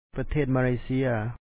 pathèet maléesía Malaysia